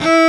STR XCELLO05.wav